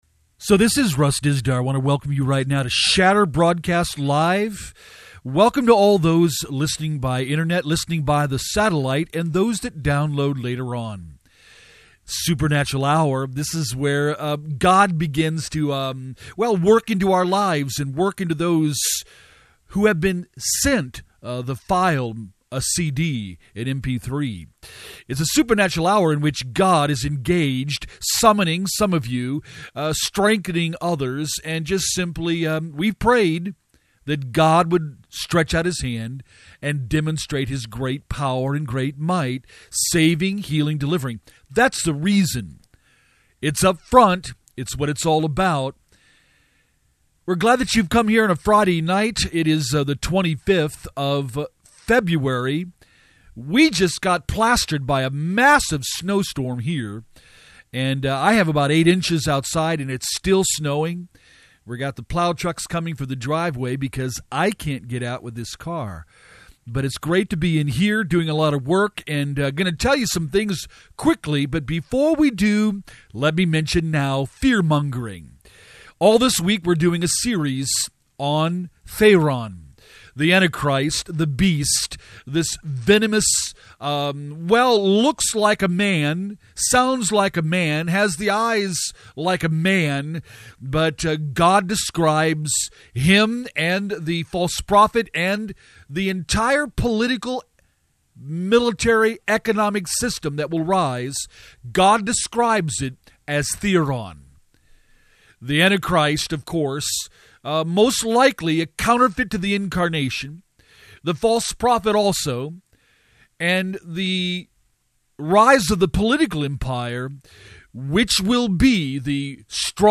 SHATTER THE DARKNESS LIVE RADIO BROADCAST INTERNET, SATELLITE AND ARCHIVES THEME FOR THE WEEK OF FEBRUARY 21st TO FEBRUARY 25TH 2011 STUDY NOTES �THERION� THE BEAST, THE BEAST SYSTEM AND THE �MAN� WHO SHAKES THE WORLD TO ITS CORE!